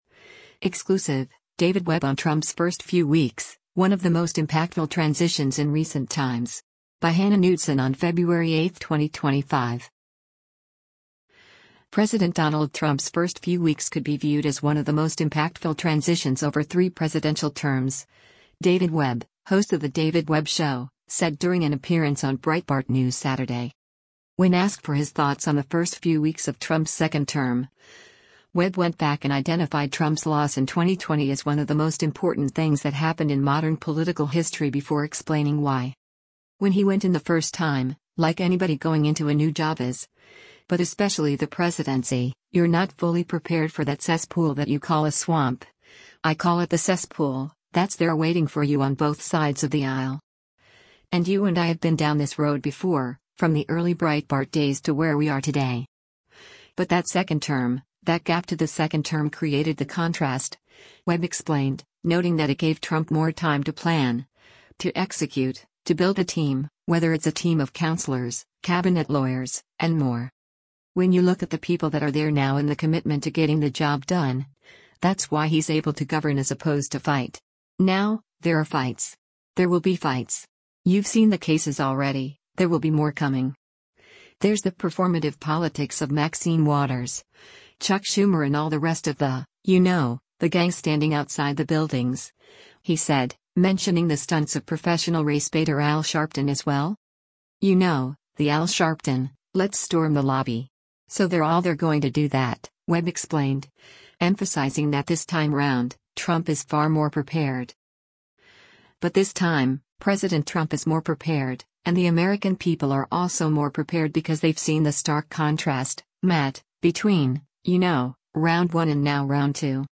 President Donald Trump’s first few weeks could be viewed as “one of the most impactful transitions over three presidential terms,” David Webb, host of the David Webb Show, said during an appearance on Breitbart News Saturday.